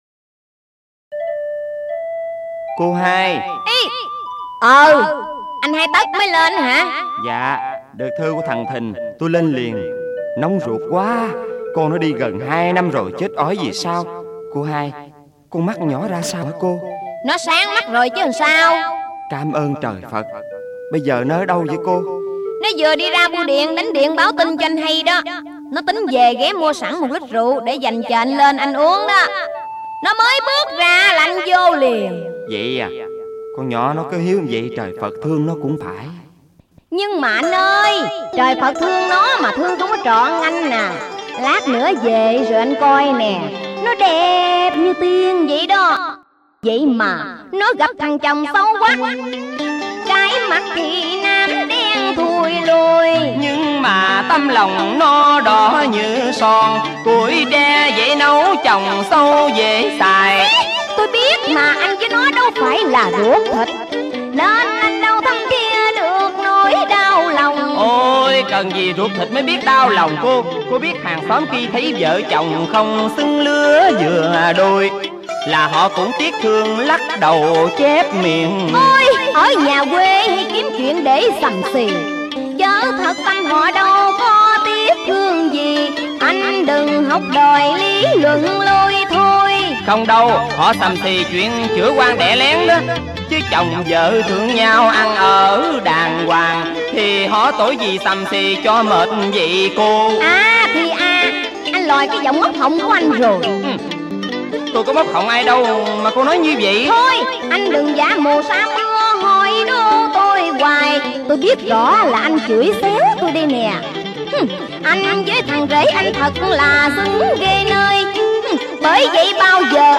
Thể loại: Tân cổ